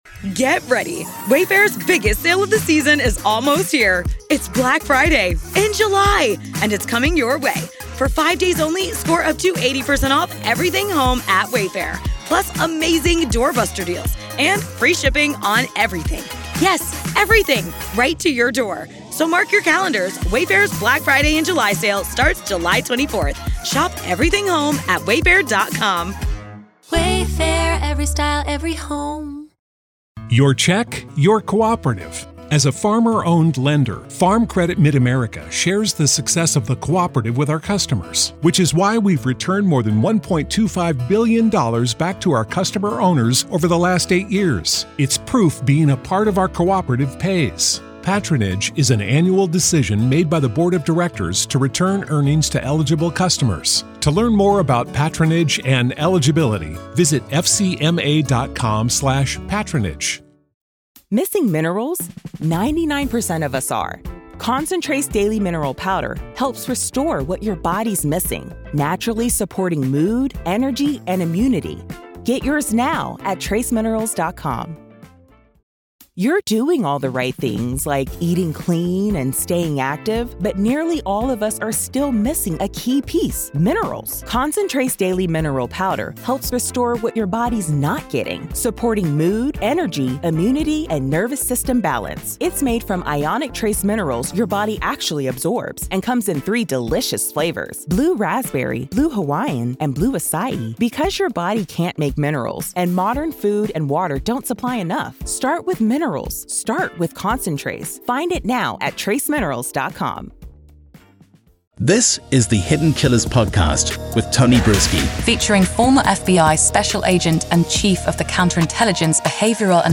The conversation illuminates several pivotal aspects of the case: Speedy Trial Approval: The decision to proceed with a speedy trial, set to begin with jury selection on May 13th, has been met with relief and concern for ensuring justice for both Richard Allen and the grieving families of the victims.